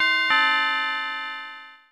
03 Alarm.aac